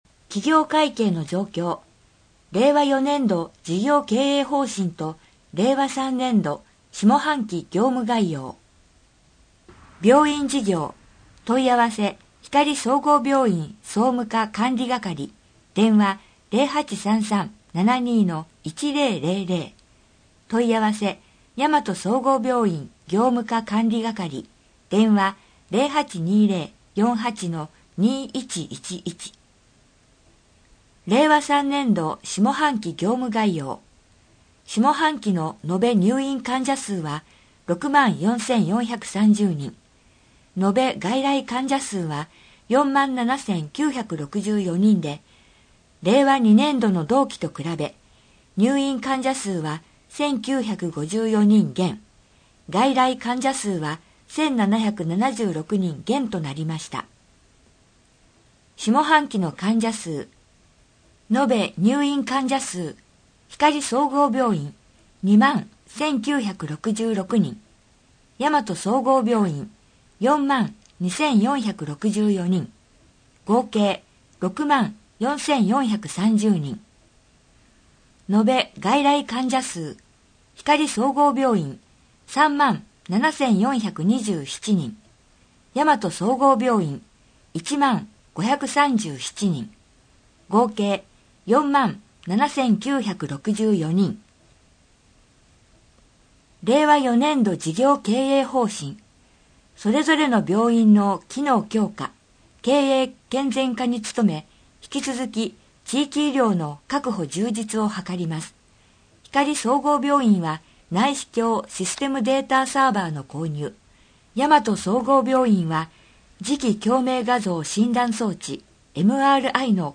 こえの広報 について 広報ひかりでは、 ボランティアグループ「こだまの会」の協力により文字を読むことが困難な視覚障害者や高齢者のために広報紙の内容を音声でもお届けしています。